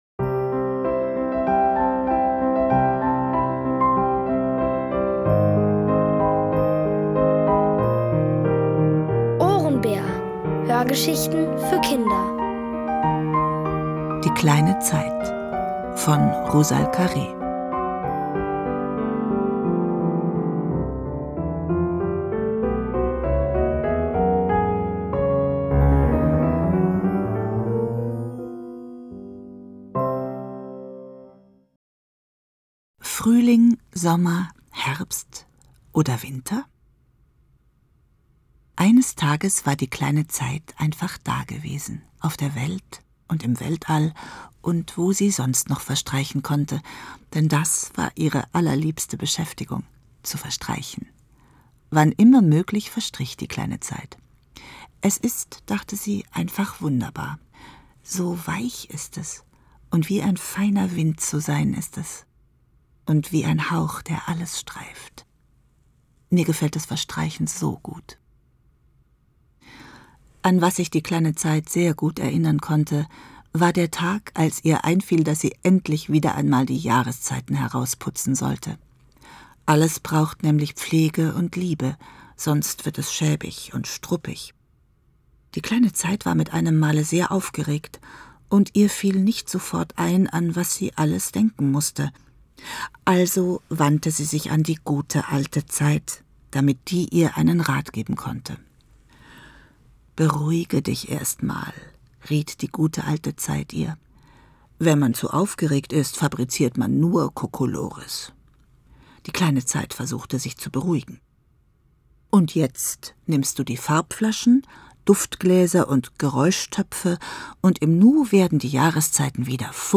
Es liest: Martina Gedeck.